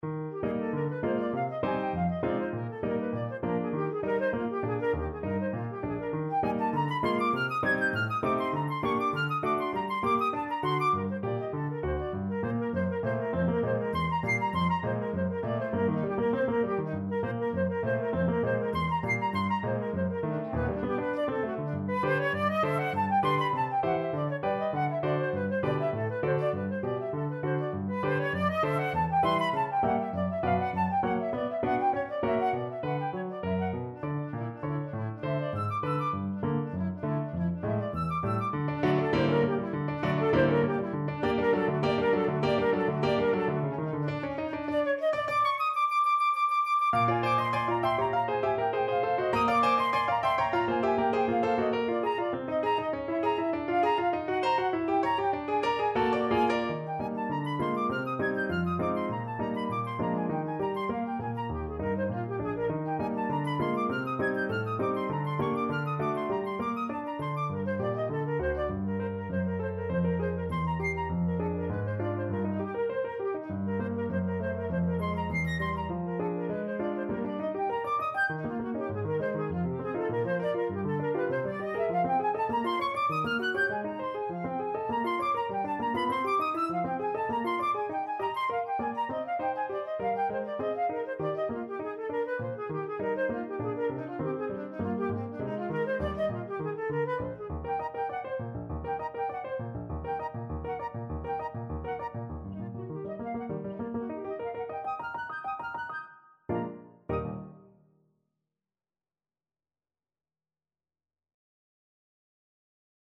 arranged for flute and piano